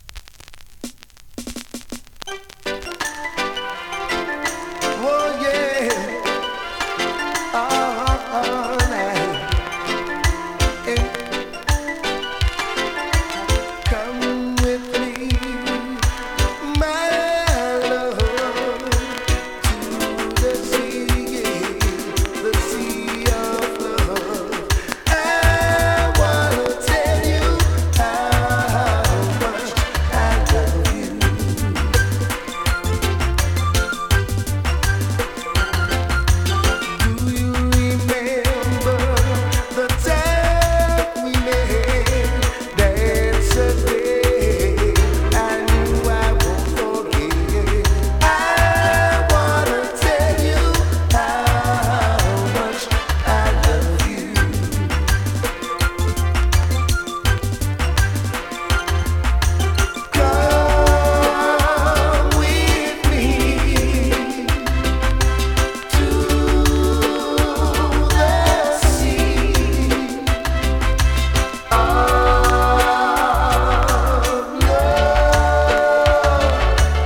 コンディションVG+(少しノイズ)
スリキズ、ノイズ比較的少なめで